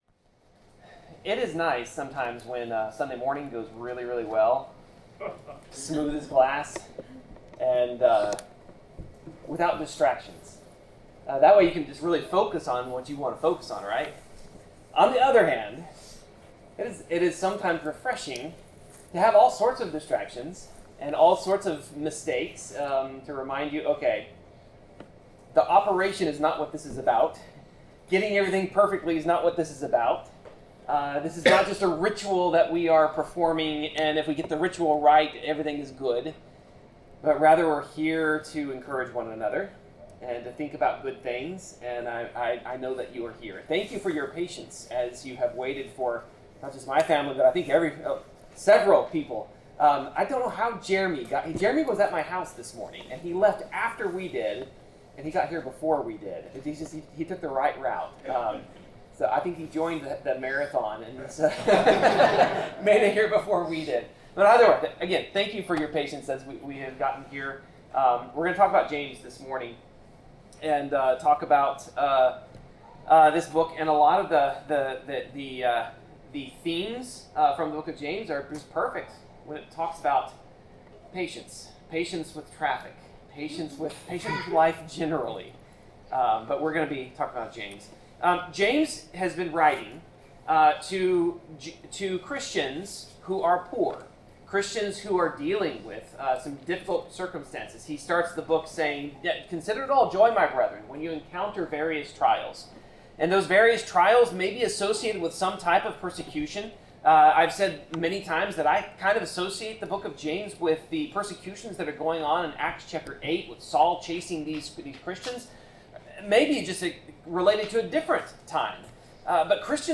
Passage: James 4:13-5:12 Service Type: Sermon